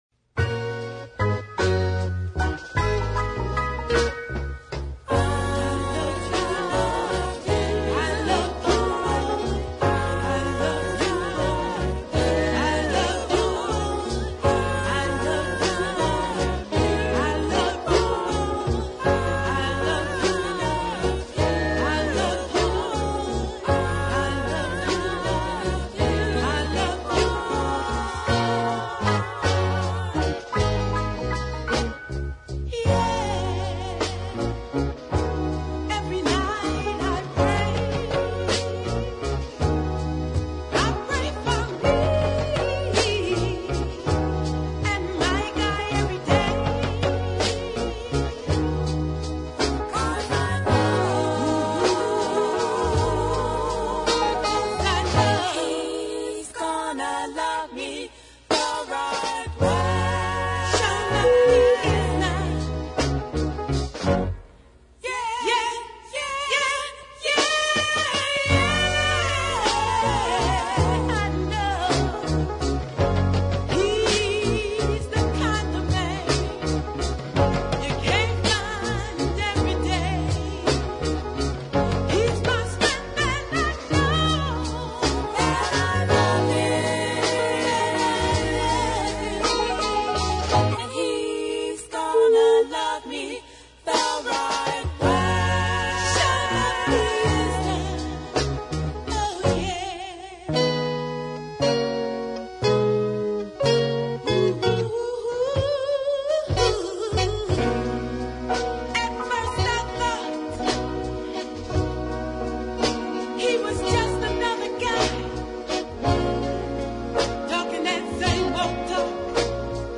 but one of them was a strong deep soul ballad.
over a chugging beat and a tasteful girl group.